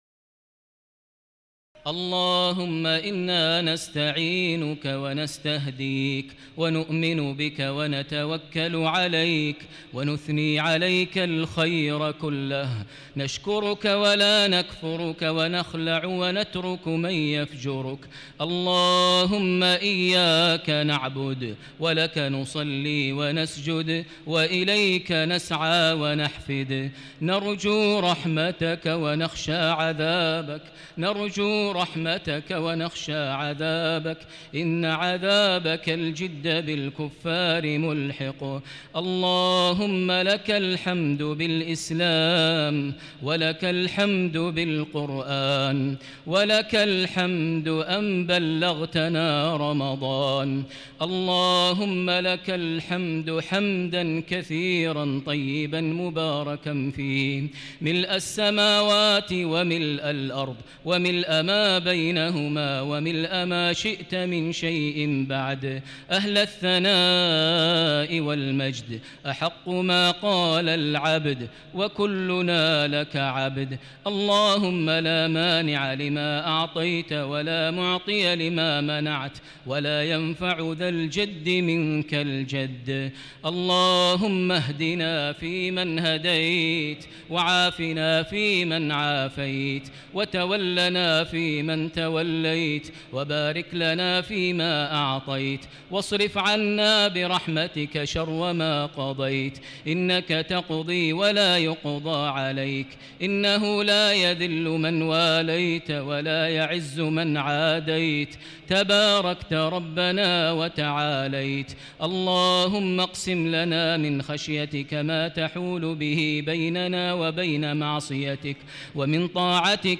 دعاء القنوت ليلة 2 رمضان 1437هـ | Dua for the night of 2 Ramadan 1437H > تراويح الحرم المكي عام 1437 🕋 > التراويح - تلاوات الحرمين